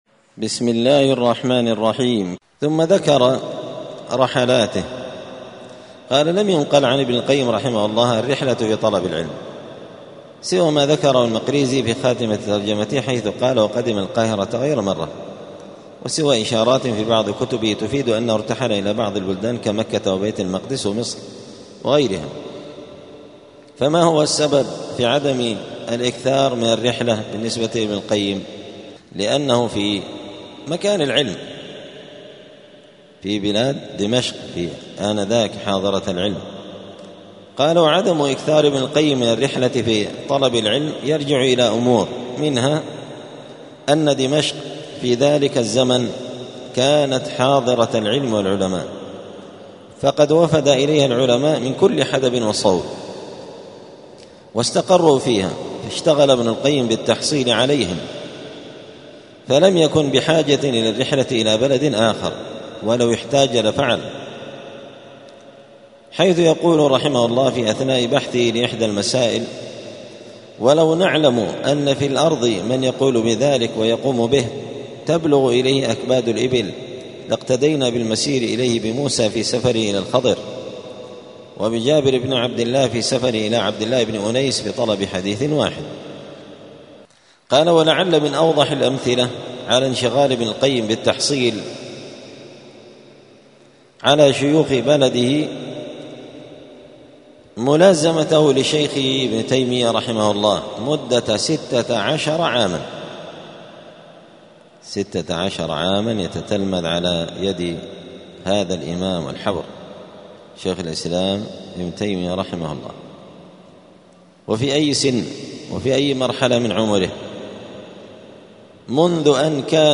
ترجمة الإمام ابن القيم رحمه الله الدرس الثاني (2) {رحلاته}
دار الحديث السلفية بمسجد الفرقان قشن المهرة اليمن